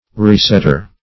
Resetter \Re*set"ter\ (r?-S?t"t?r), n.